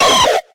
Grito de Gible.ogg
Grito_de_Gible.ogg.mp3